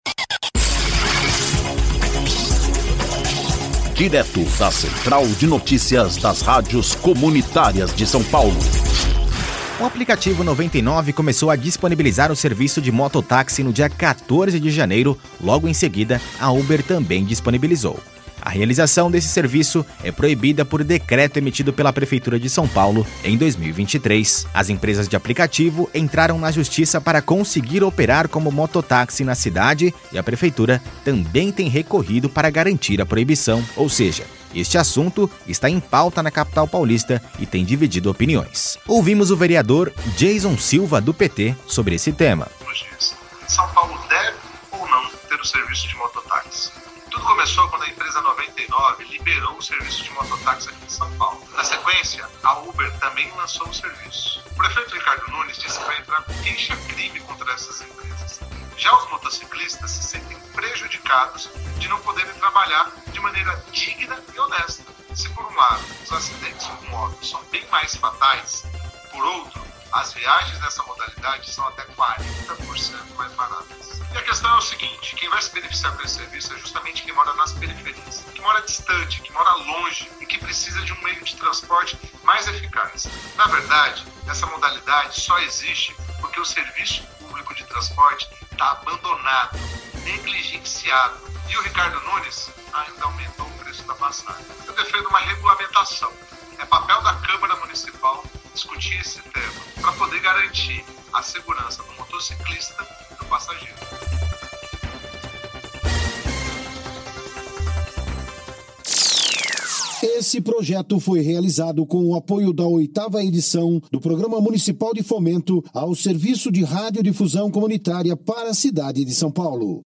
Ouça a notícia: O vereador Dheison Silva destaca necessidade de debate sobre serviço de mototáxi na cidade
Ouvimos o vereador Dheison Silva do PT sobre este tema.